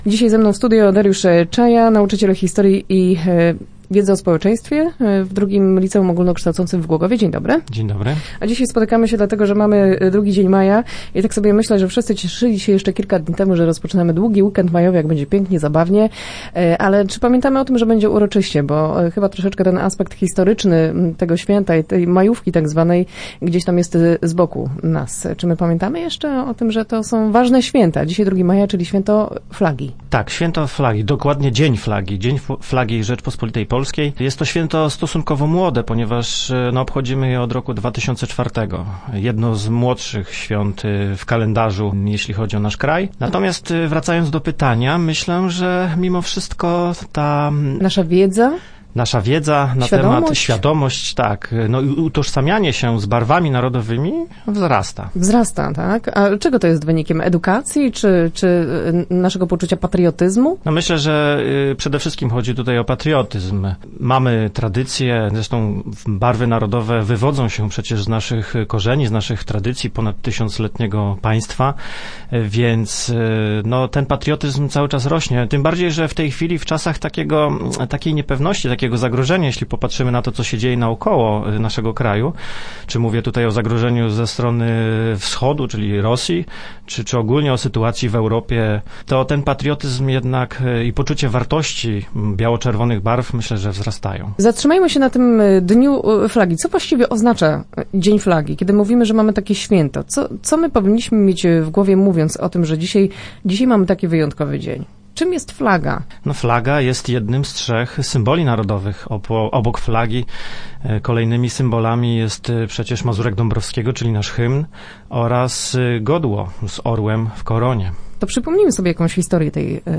dobry wywiad tak trzymać